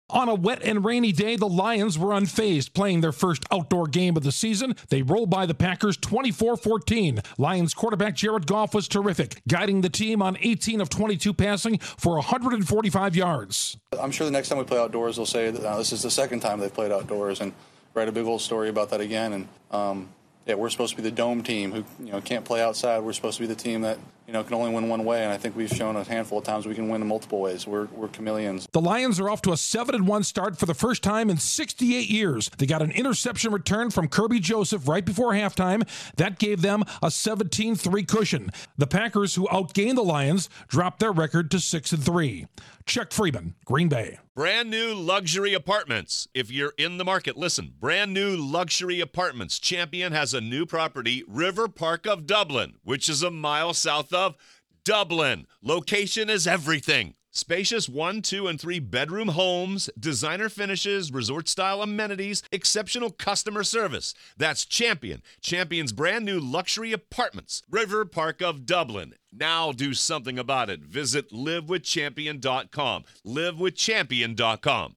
The Lions show they're more than an indoor team. Correspondent